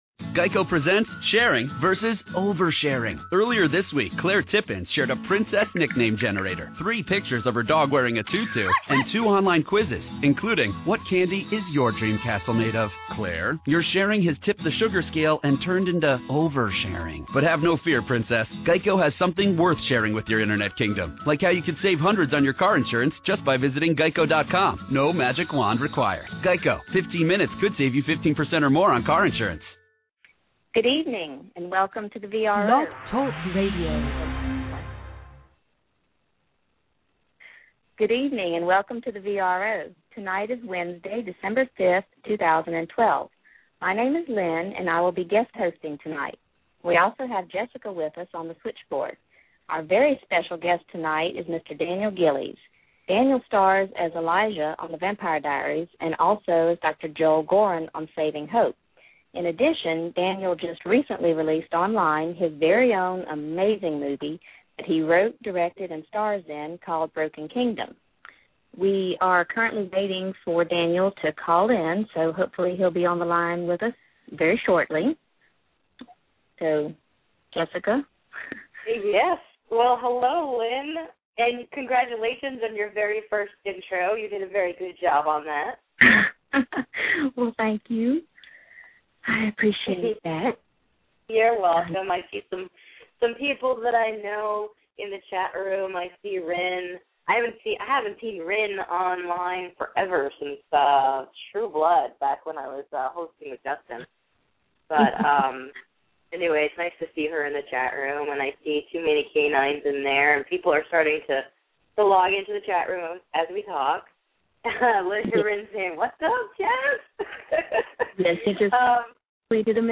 Daniel Gillies - Broken Kingdom Interview